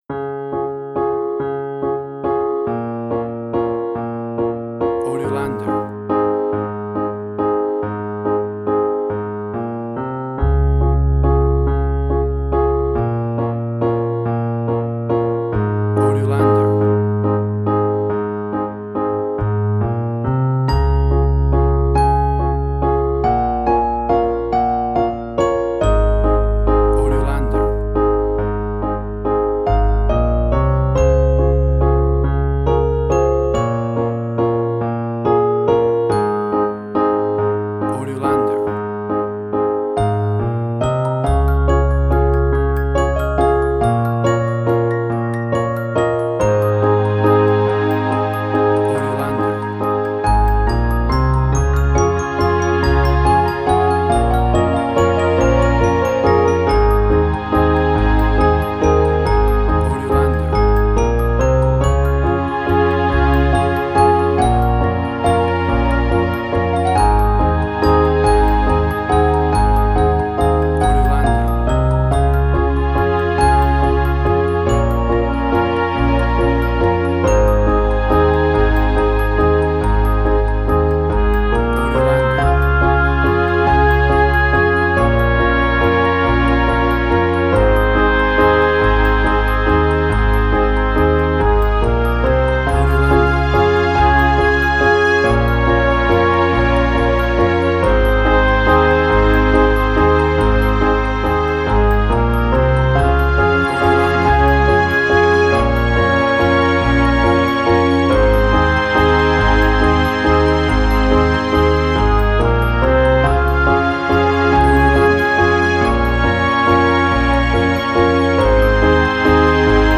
Indie Quirky
Tempo (BPM): 140